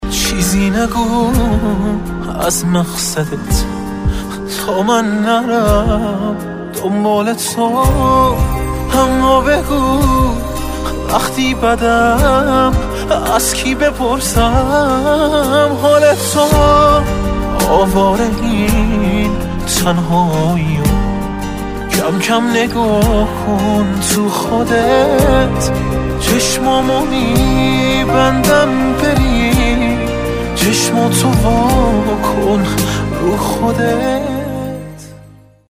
زنگ موبایل عاشقانه و با کلام